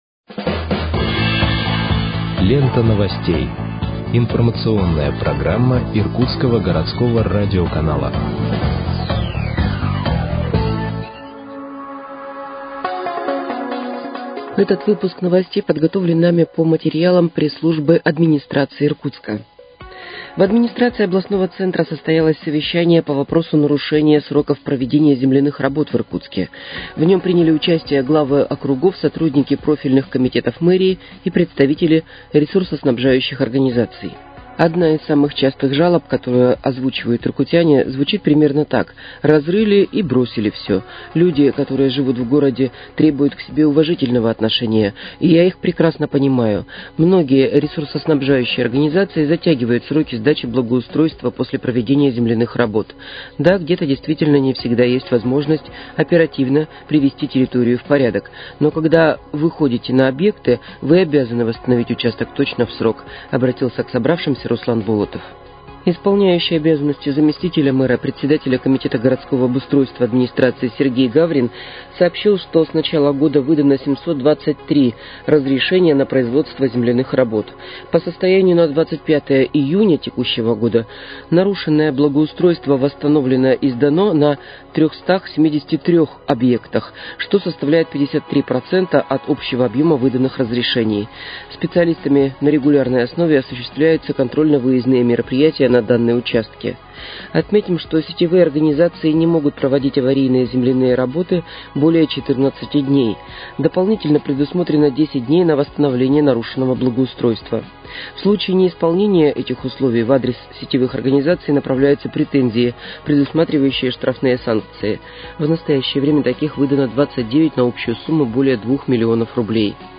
Выпуск новостей в подкастах газеты «Иркутск» от 02.07.2025 № 2